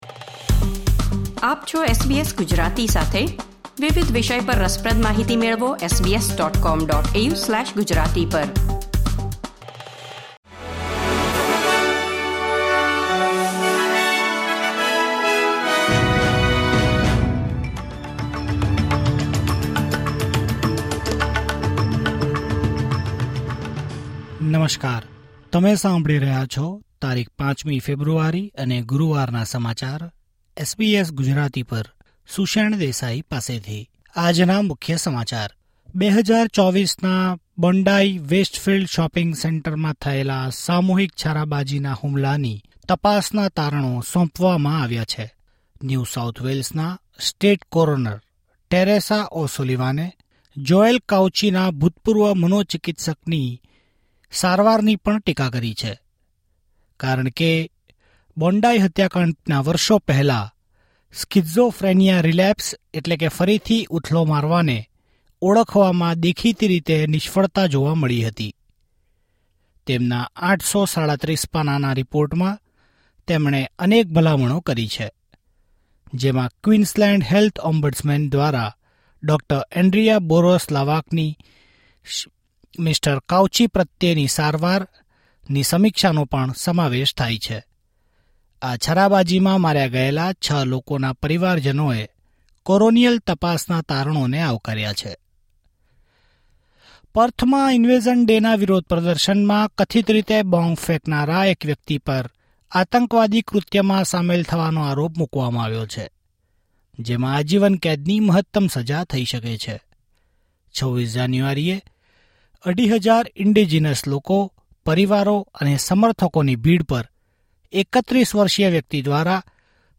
Listen to the latest Australian news from SBS Gujarati